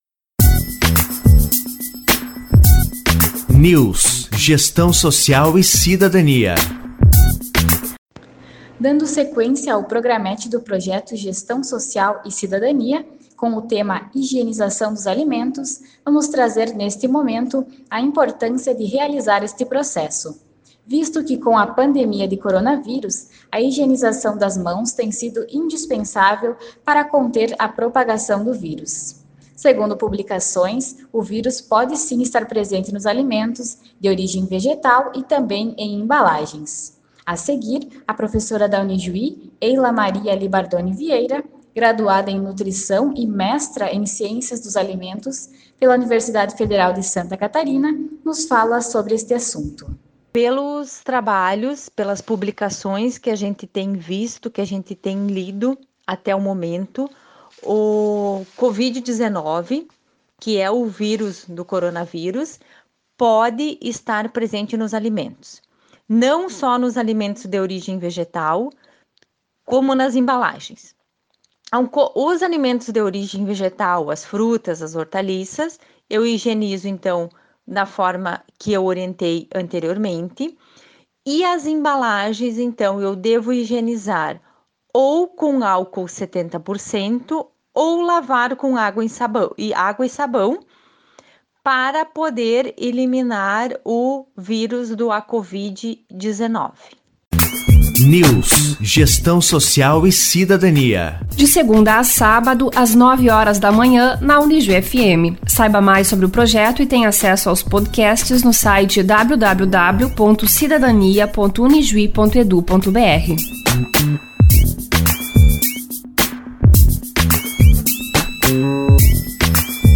Entrevistada